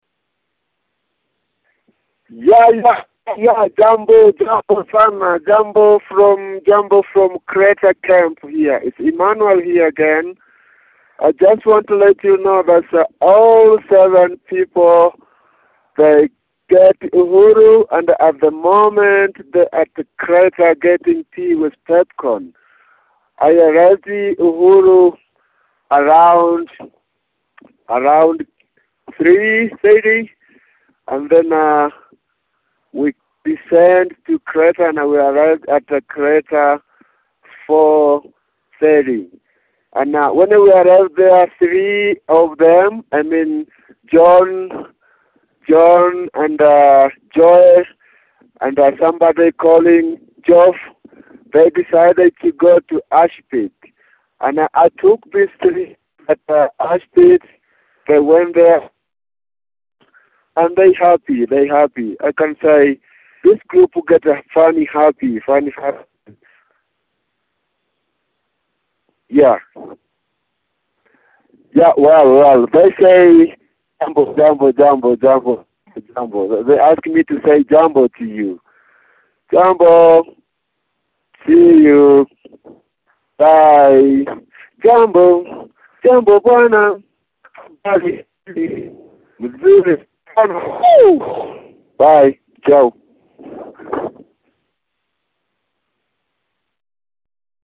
Kilimanjaro Expedition Dispatch